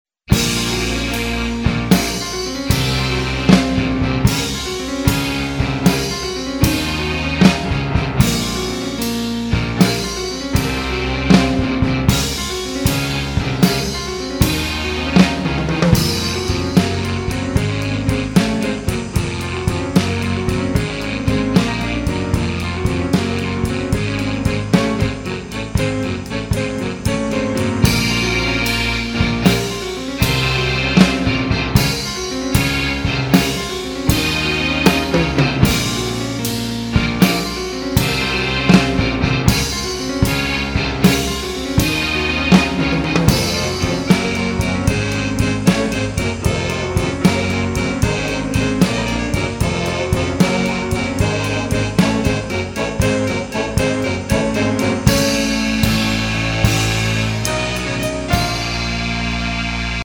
acoustic piano
drums and percussives